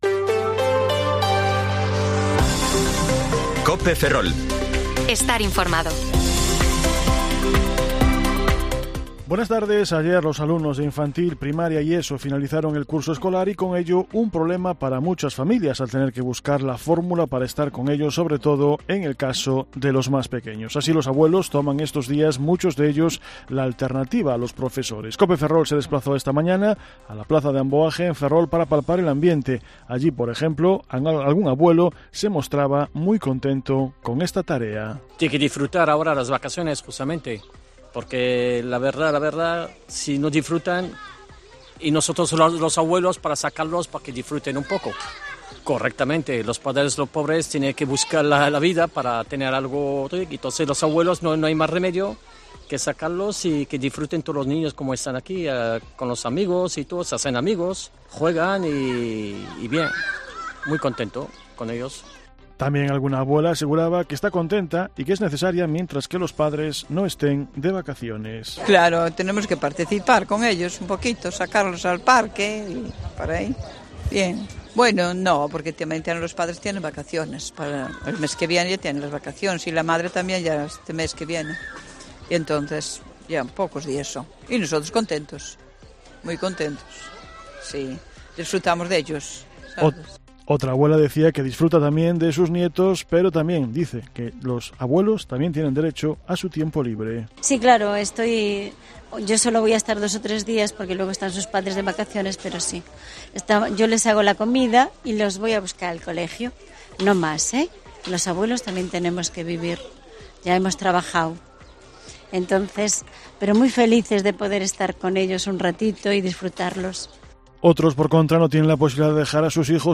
Informativo Mediodía COPE Ferrol 22/6/2023 (De 14,20 a 14,30 horas)